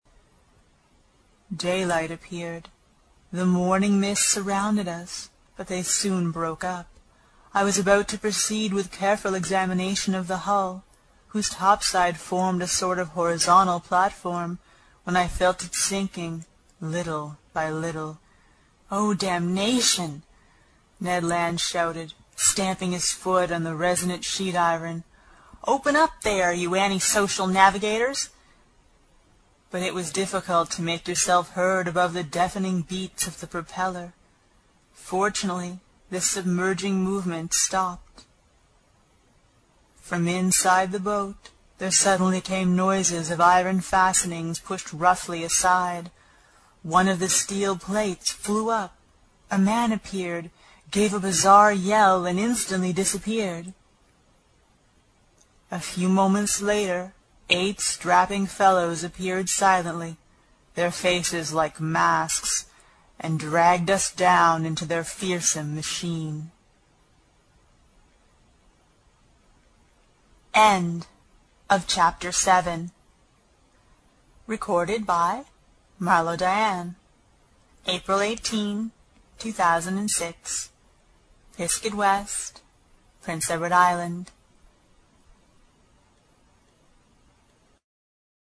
英语听书《海底两万里》第97期 第7章 一种从未见过的鱼(20) 听力文件下载—在线英语听力室